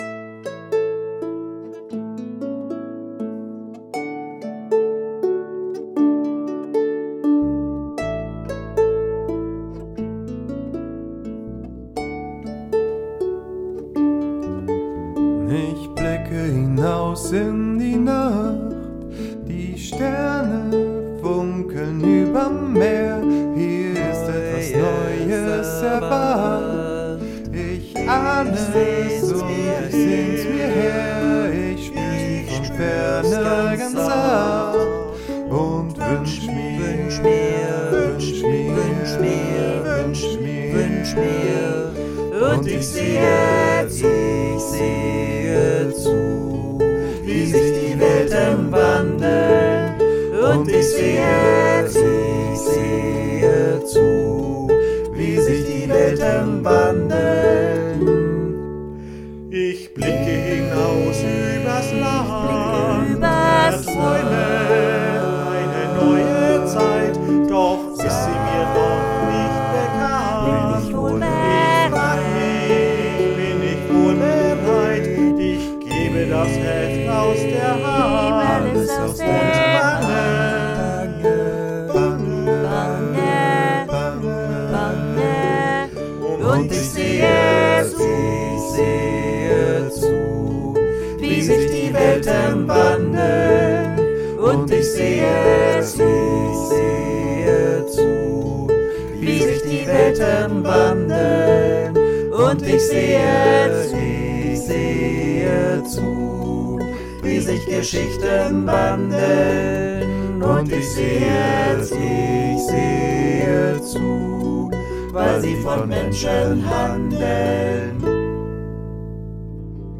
Tenor
Bariton